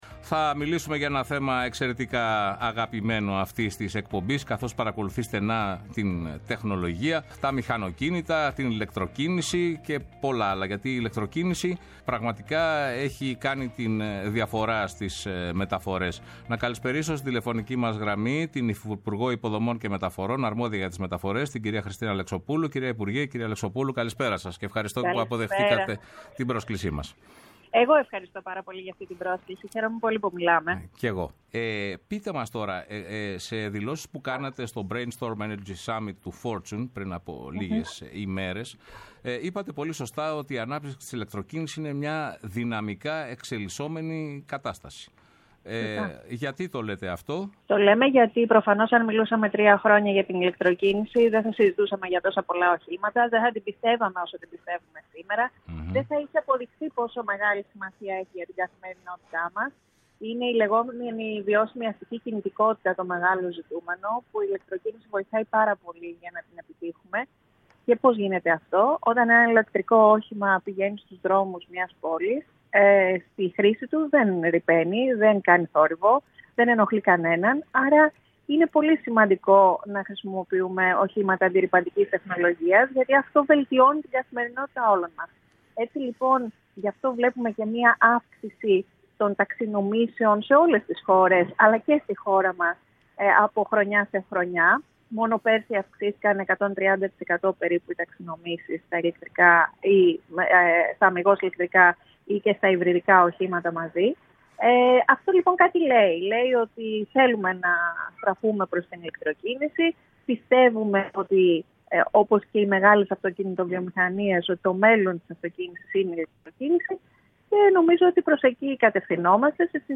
Η Υφυπουργός Μεταφορών στο Πρώτο Πρόγραμμα | 09.04.2024